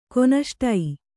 ♪ konaṣtai